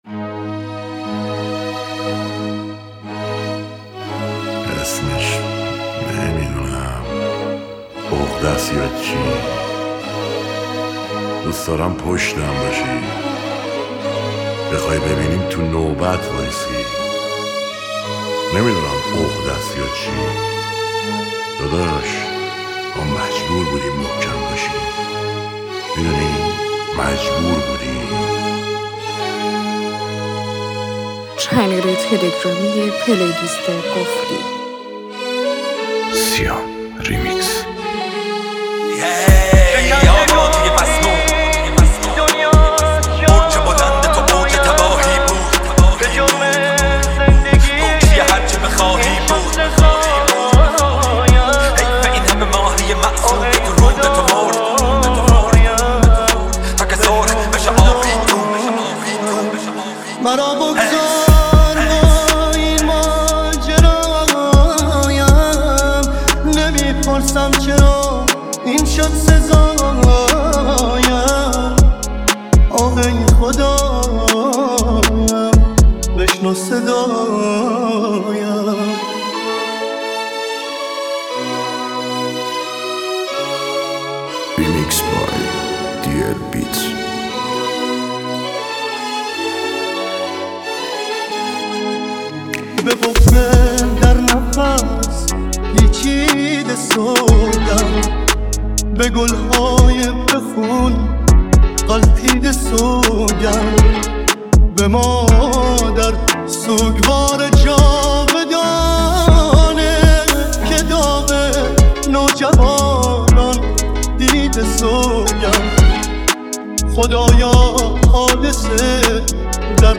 ریمیکس رپی
ریمیکس جدید رپ